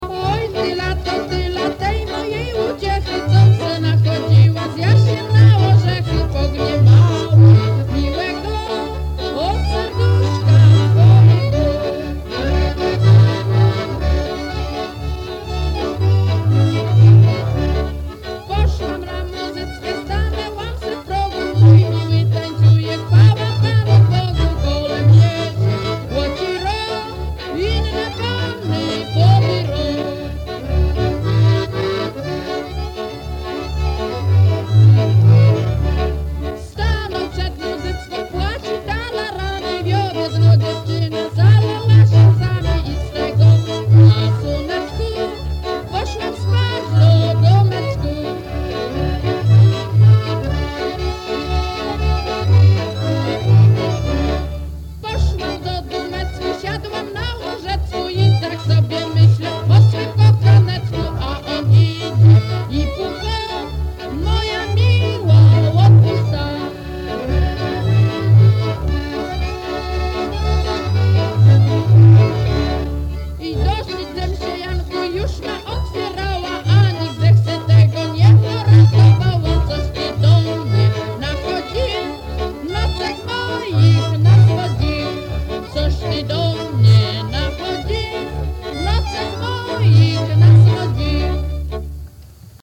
Nagranie archiwalne